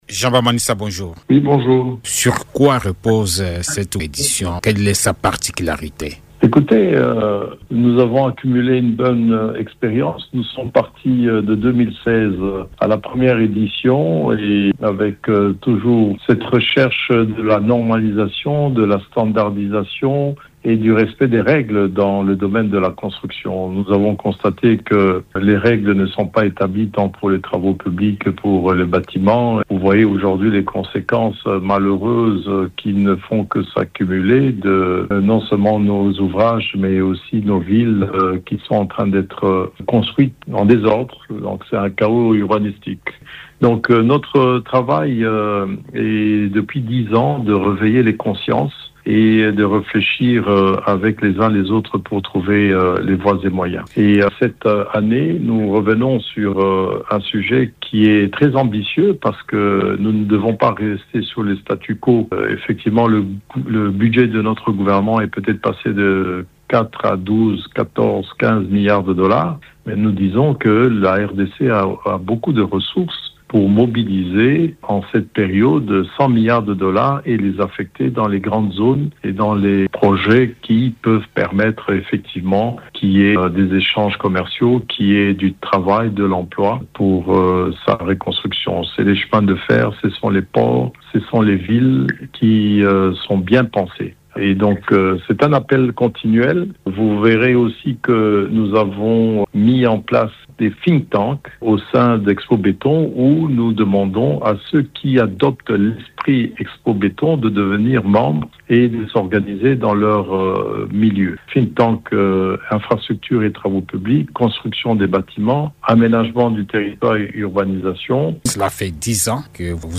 À la veille de l’ouverture officielle des travaux, Jean Bamanisa Saidi, promoteur d’ExpoBéton, était l’invité de Radio Okapi pour évoquer l’importance de ce salon ainsi que les dix années de son existence.